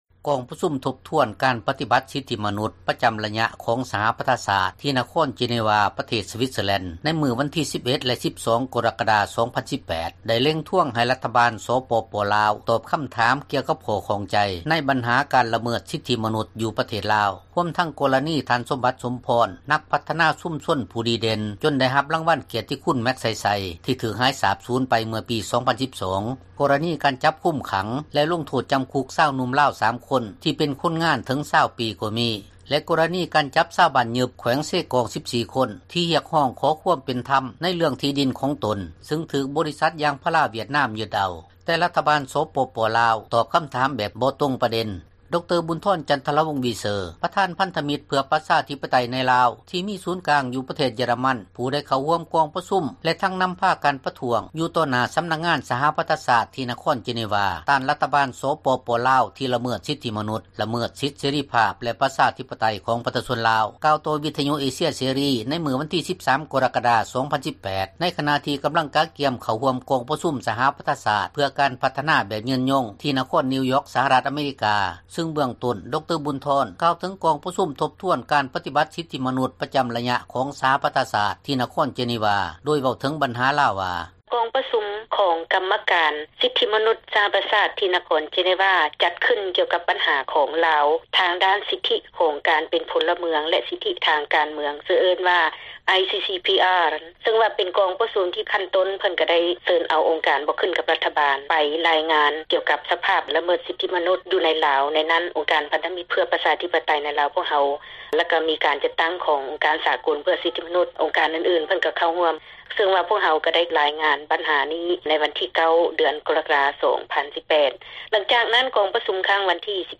ມີບົດສຳພາດ